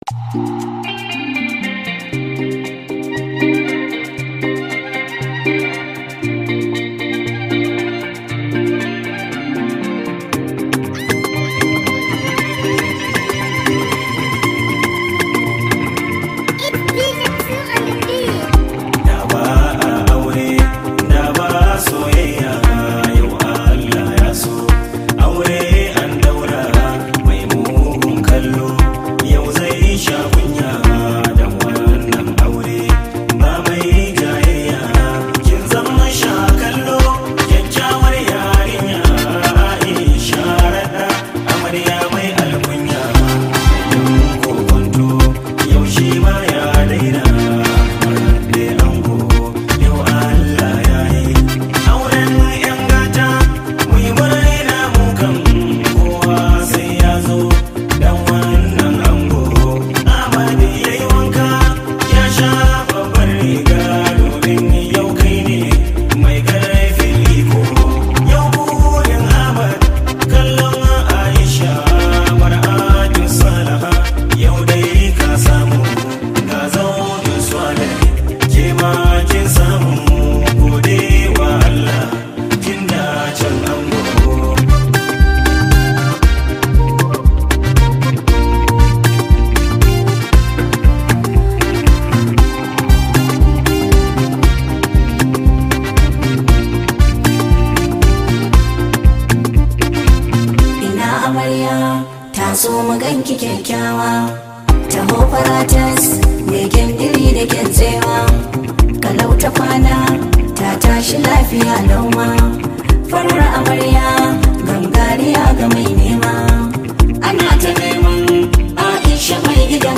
Category: Hausa Songs
Hausa Song